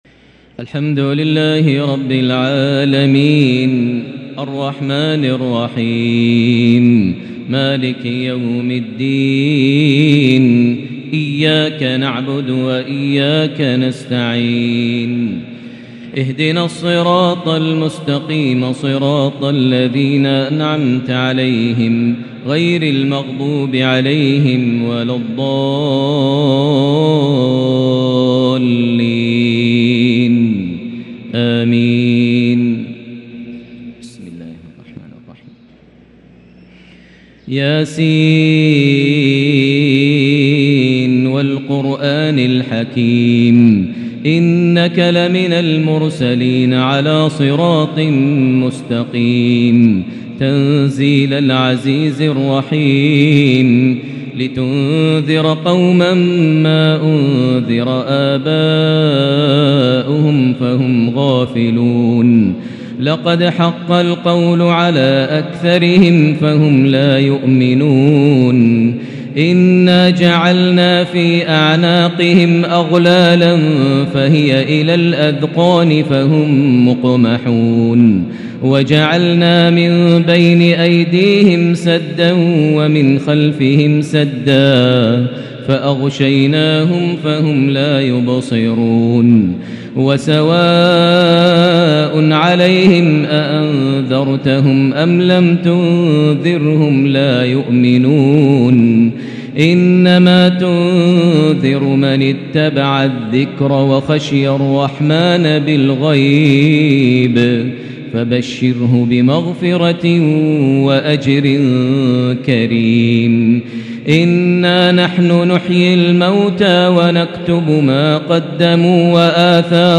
ياليلة الختام ماأشجاك| تهجد ليلة 29 رمضان 1443هـ| سورة يس كاملة بتراتيل شجية > تراويح ١٤٤٣هـ > التراويح - تلاوات ماهر المعيقلي